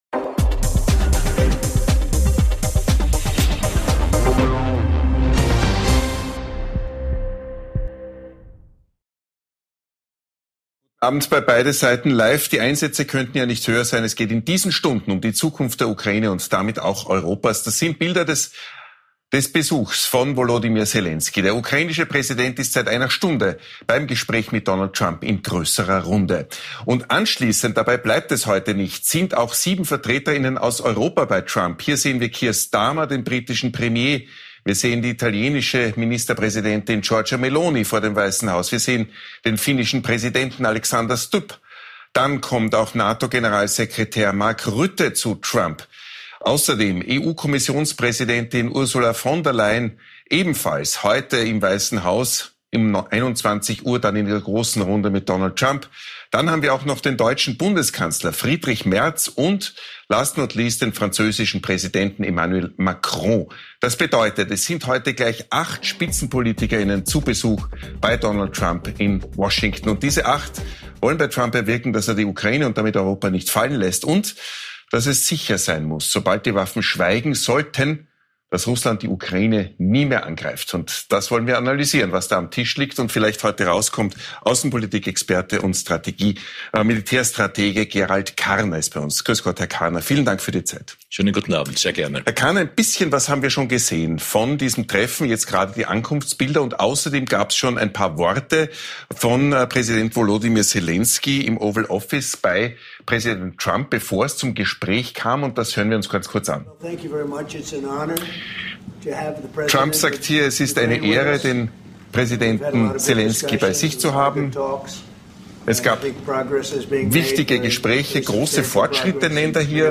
Selenskyj samt EU Delegation trifft Donald Trump in Washington um über die Situation in der Ukraine zu verhandeln. Und ein Blick in den politischen Herbst mit einer Runde aus Polit-Profis.